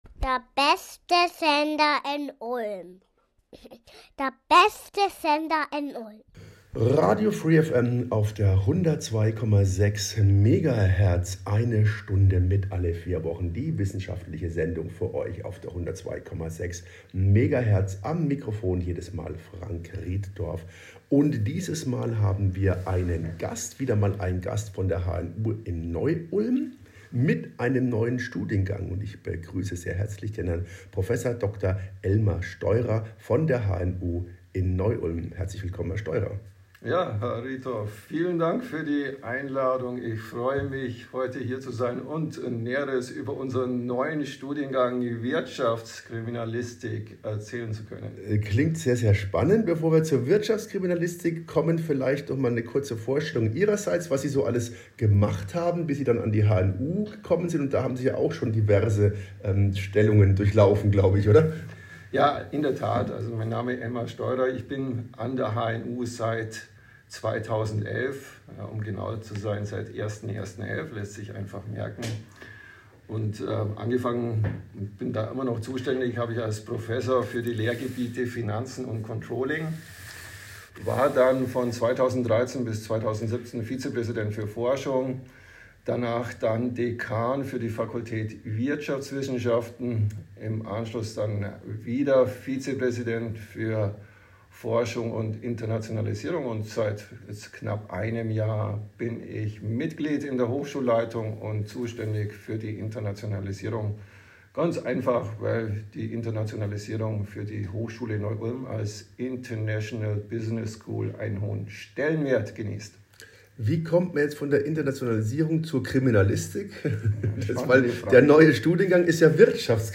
Es ist eine Sendung mit Themen aus Wissenschaft und Forschung. Ein Gast (zumeist von der Uni Ulm) berichtet aus seinem Fachgebiet über ein Thema und erklärt uns die Welt der Wissenschaft in einer Stunde.